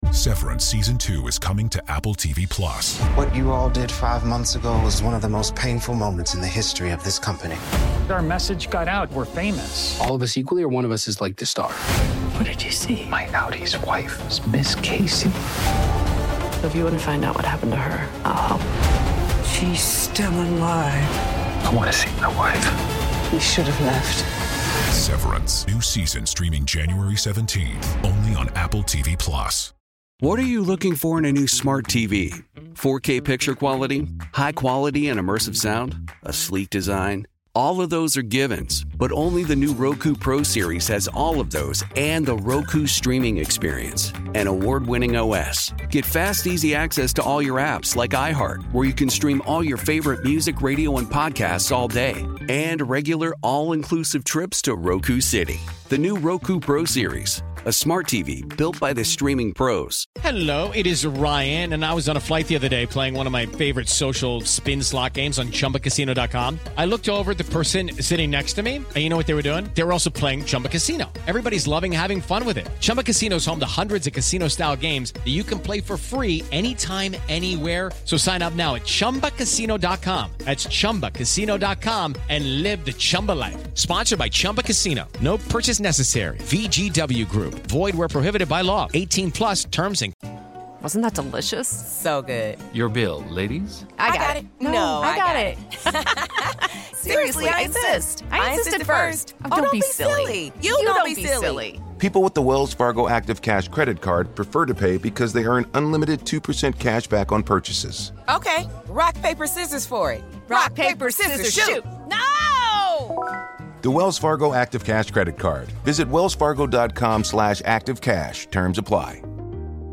On this episode of Our American Stories, just moments before the biggest fireworks display in American history, President Ronald Reagan spoke in front of the Statue of Liberty aboard the USS John F. Kennedy. Reagan understood the profound nature of what the founders did back in 1776. We take you back to New York Harbor in 1986.